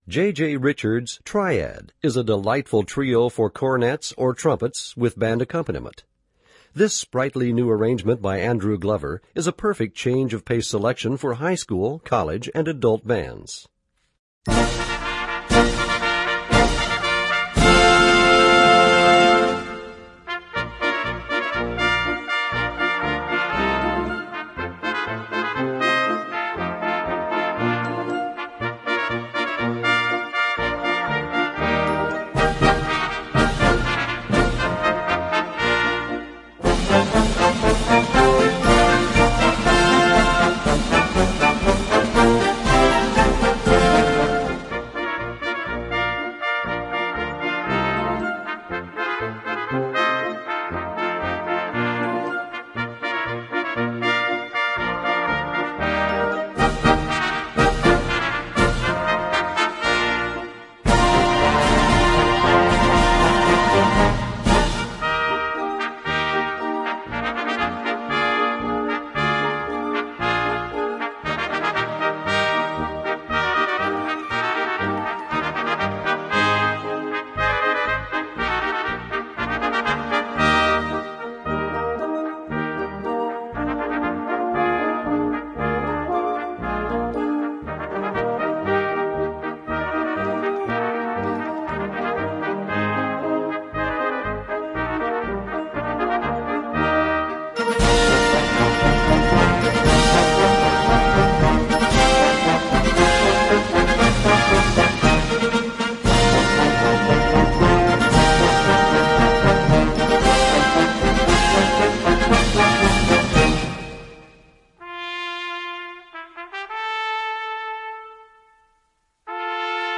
Voicing: Trumpet Trio w/ Band